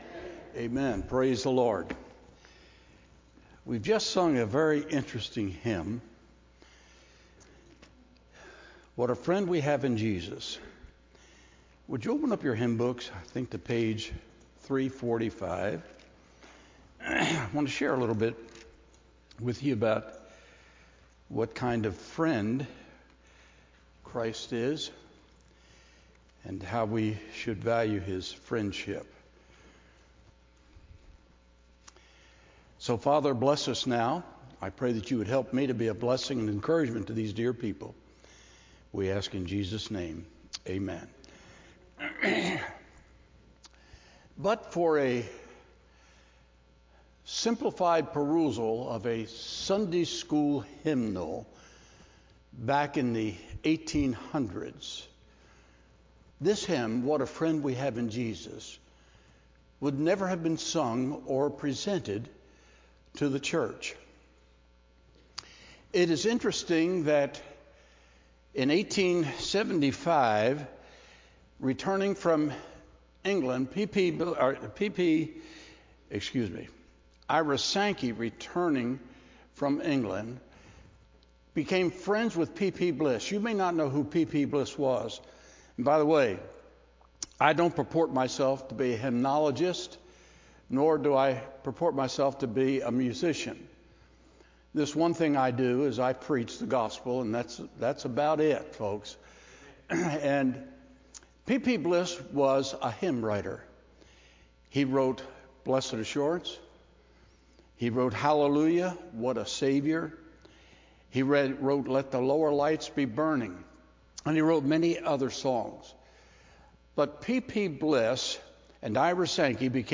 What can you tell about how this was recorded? Sunday PM Service